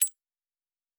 Bullet 2_1.wav